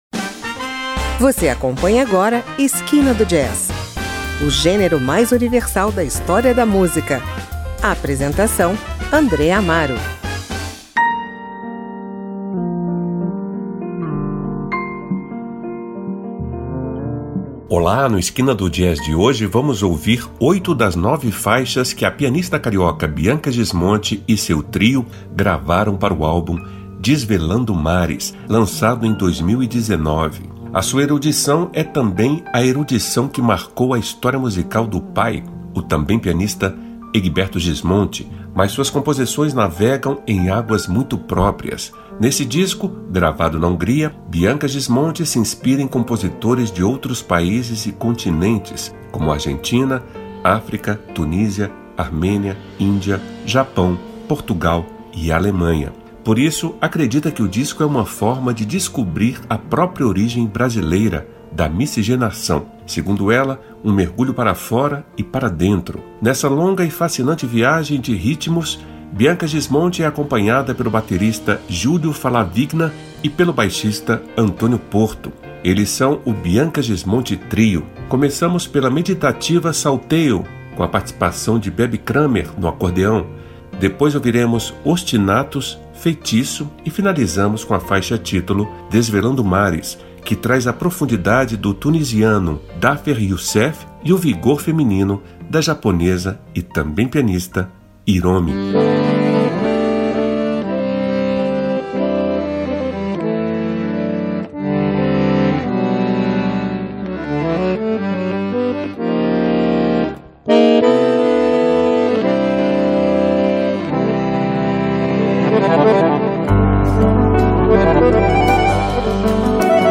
pianista
baterista
baixista